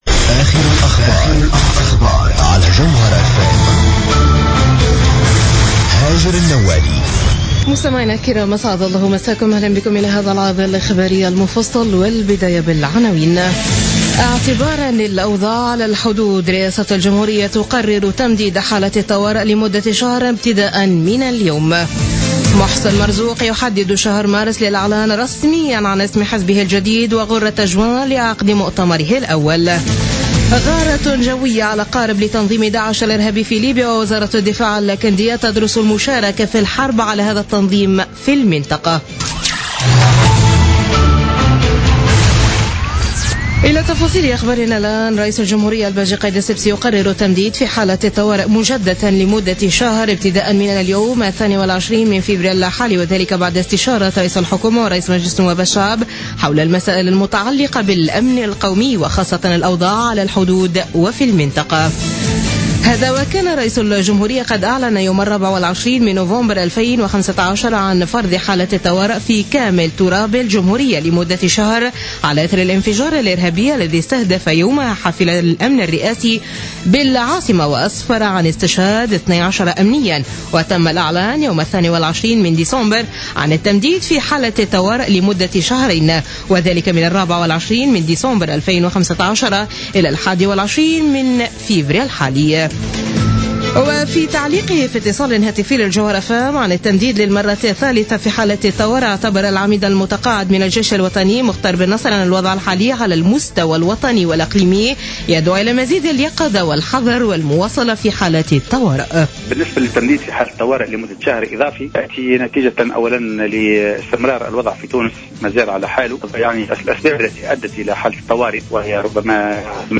نشرة أخبار منتصف الليل ليوم الاثنين 22 فيفري 2016